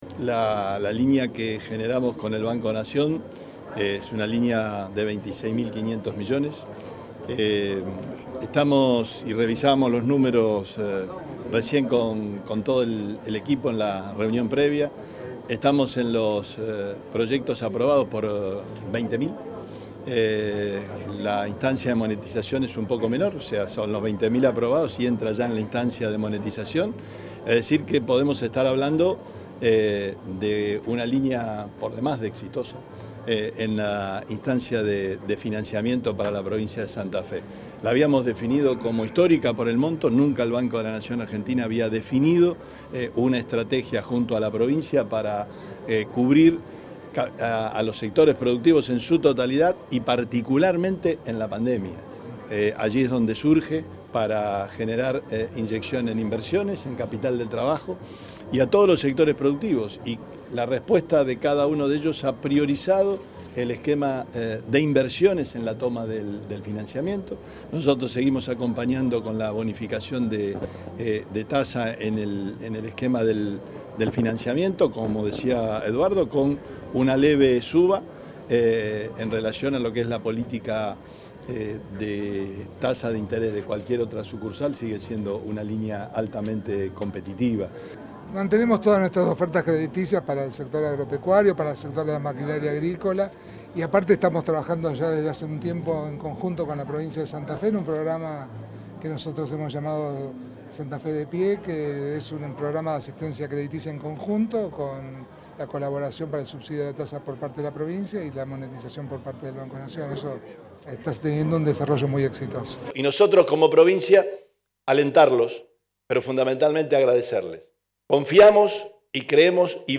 Declaraciones del gobernador Perotti y de Eduardo Hecker